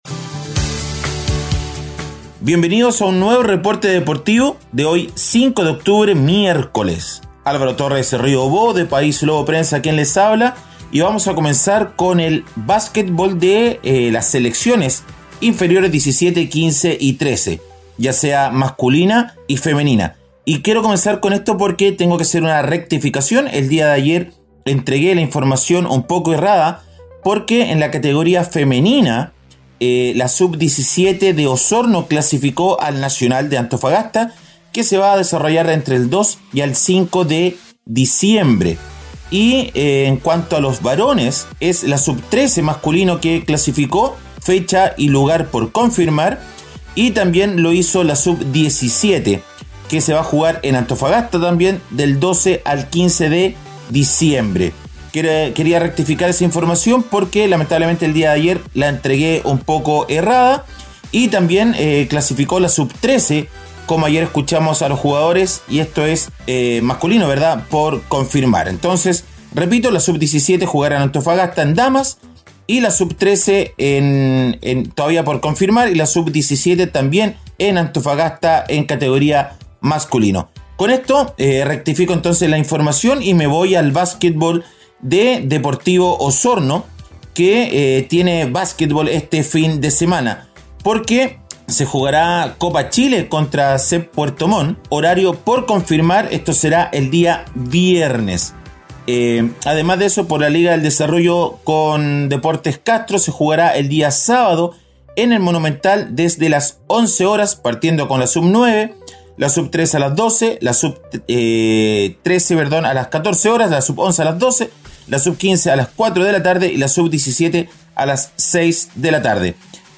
nos entrega un breve reporte con las "deportivas" más destacadas de las últimas 24 horas.